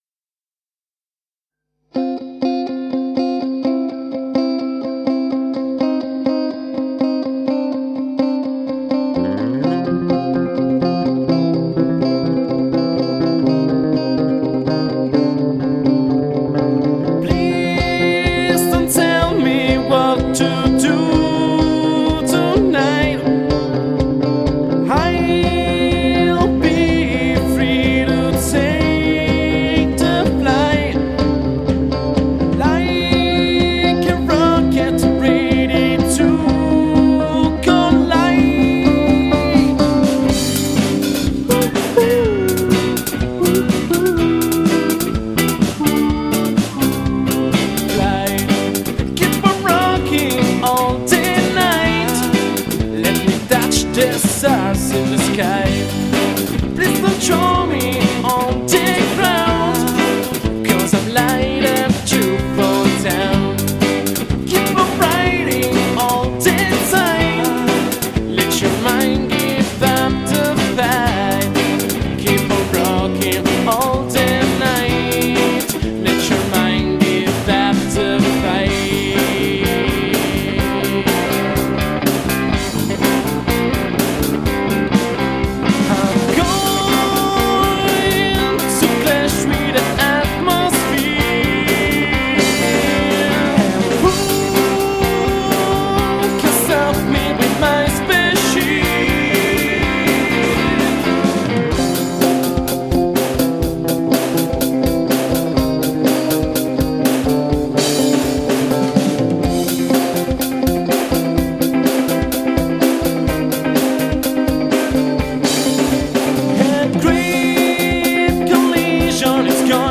Genere: Rock - Hard Blues
chitarra solista
voce/chitarra
batteria
basso